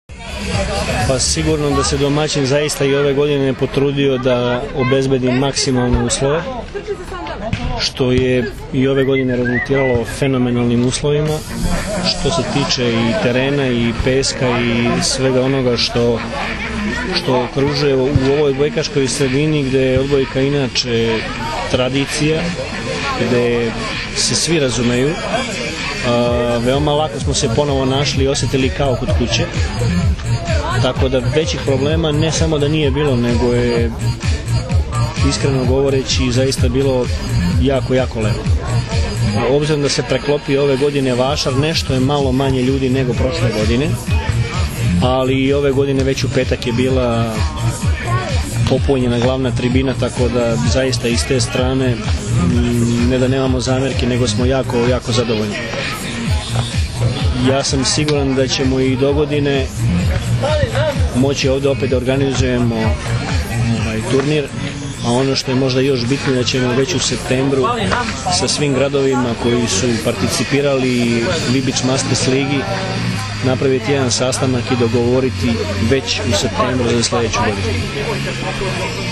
IZJAVA VLADIMIRA GRBIĆA 1